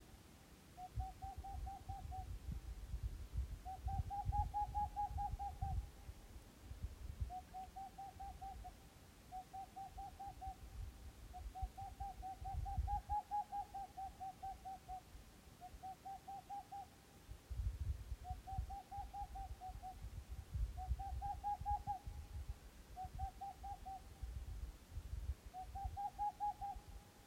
Sist kveld var det minus 18, og fuglen befant seg hundre meter unna mens jeg gjorde opptaket.
En gang satte den seg i treet utenfor soverommet og begynte å gale med sin kraftige, mollstemte lyd.
Dette er sangen til ei perleugle.